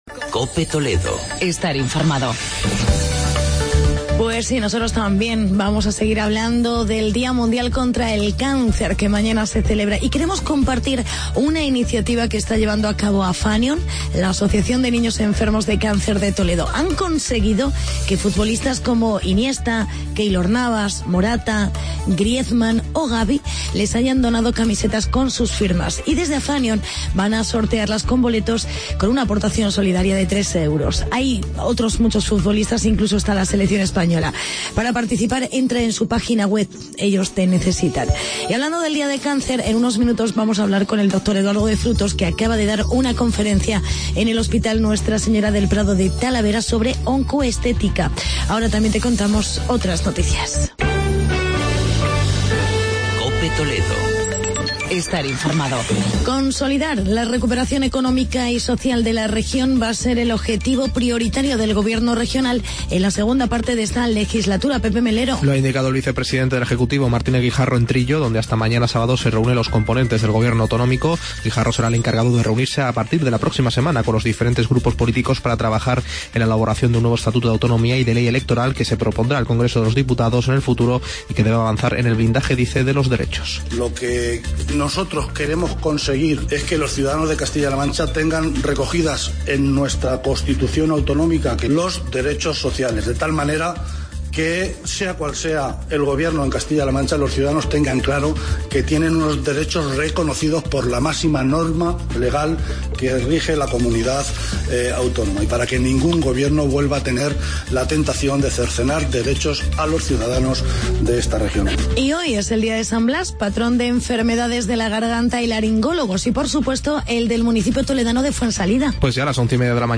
Magazine
entrevista